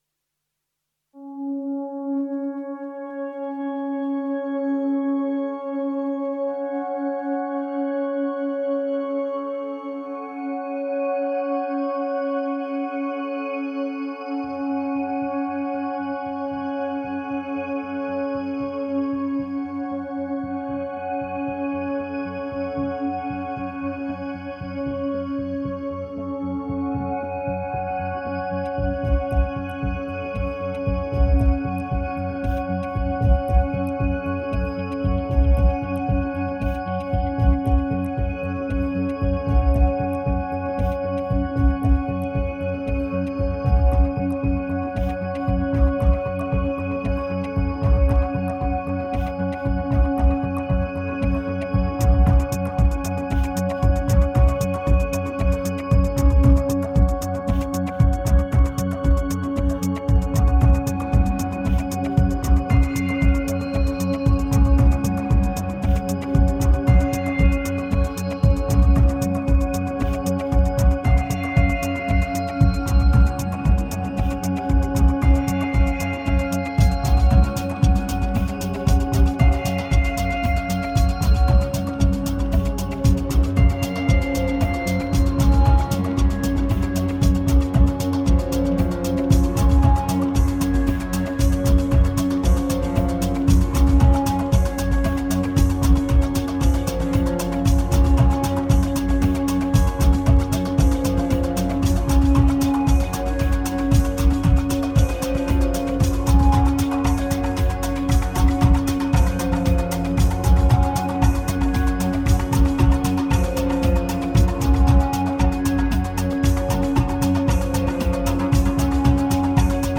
1796📈 - 67%🤔 - 115BPM🔊 - 2013-06-28📅 - 228🌟